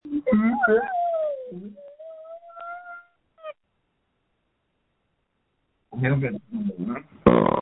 • When you call, we record you making sounds. Hopefully screaming.